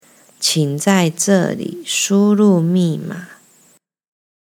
チン ザイ ヂェ゛ァ リー シュ ルー ミィ マー
qǐng zài zhè lǐ shū rù mì mǎ